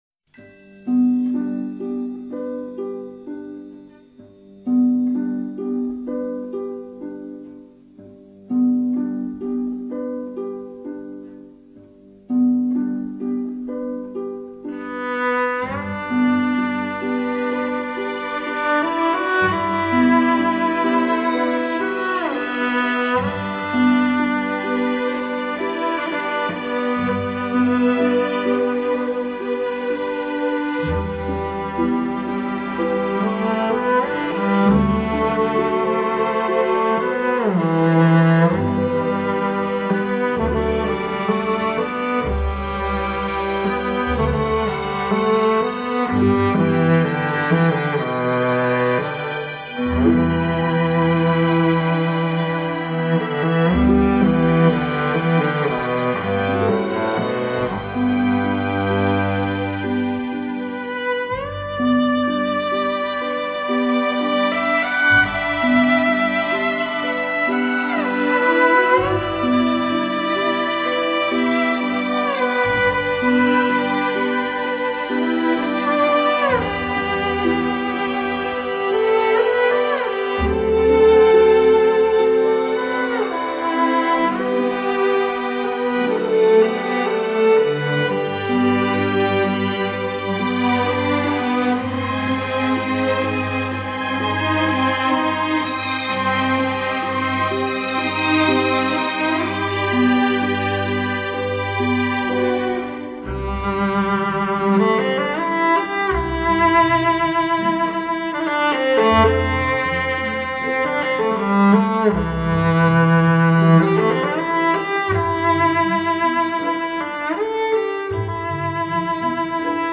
打开360度听觉层面
把每种乐器的声色伸展到极至